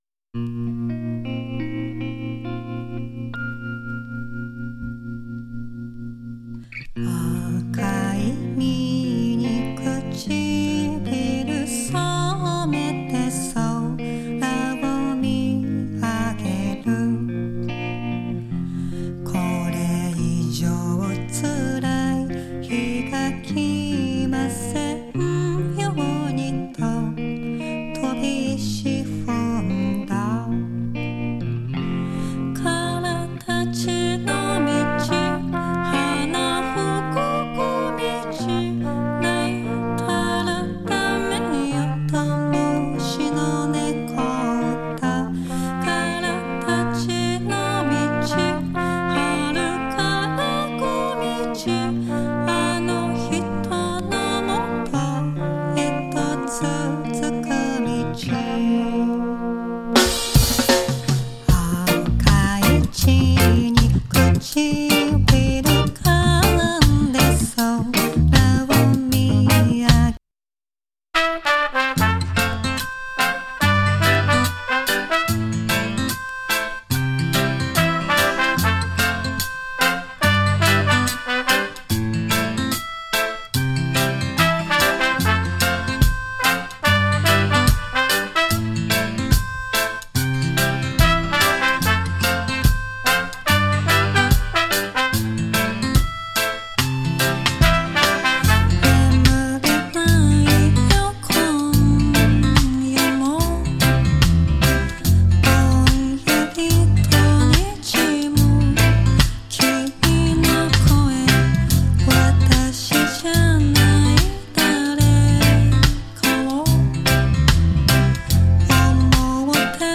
7inch